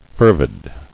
[fer·vid]